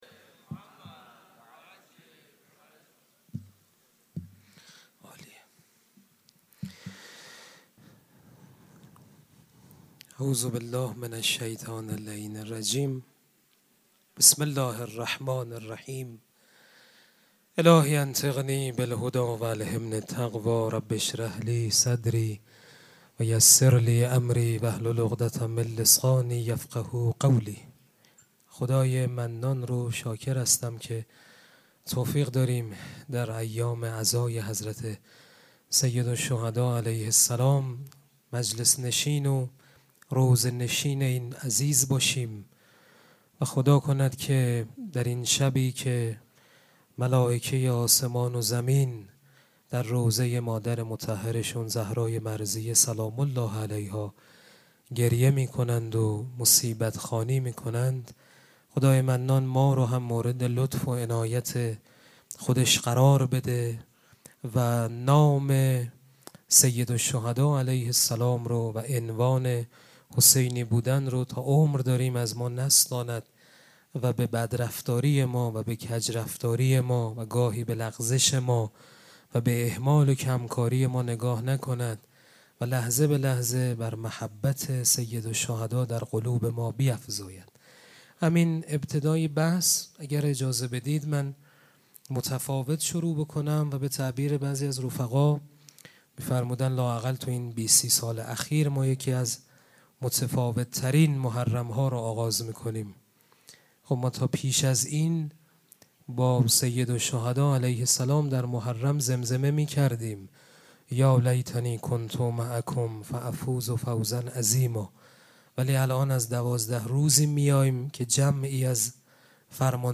سخنرانی
مراسم عزاداری شب اول محرم الحرام ۱۴۴۷ پنجشنبه ۵ تیر ماه ۱۴۰۴ | ۳۰ ذی‌الحجه ۱۴۴۶ حسینیه ریحانه الحسین سلام الله علیها
sokhanrani.mp3